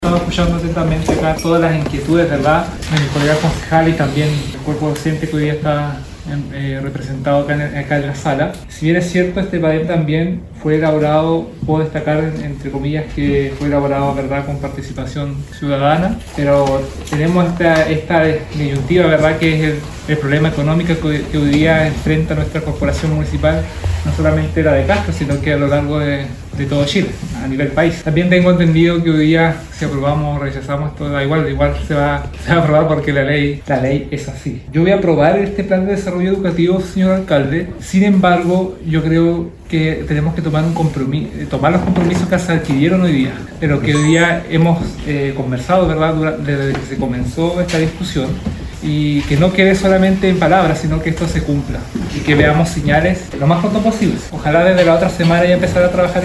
A su vez, el Concejal Nicolás Álvarez, se manifestó a favor de aprobar dicho plan y solicitó que los compromisos adquiridos en torno a él se cumplan: